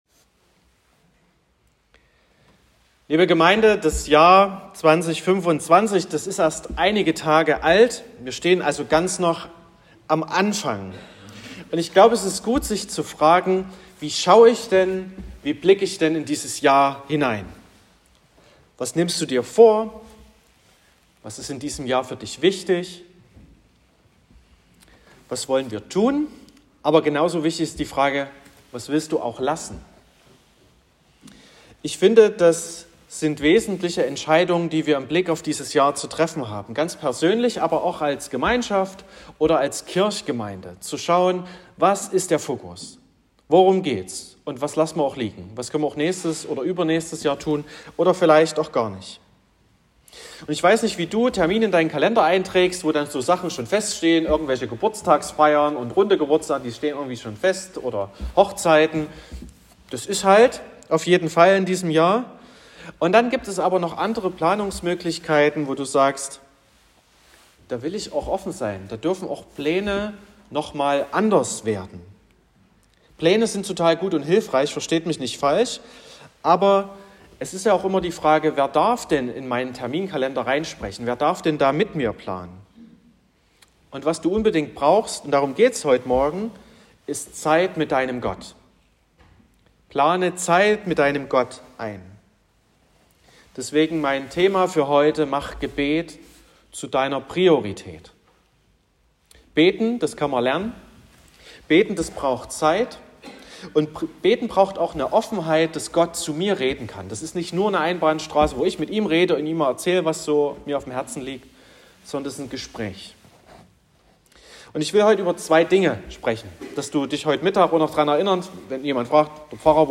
12.01.2025 – Gottesdienst zur Allianz-Gebetswoche
Predigt (Audio): 2025-01-12_Mach_Gebet_zu_deiner_Prioritaet_.m4a (11,1 MB)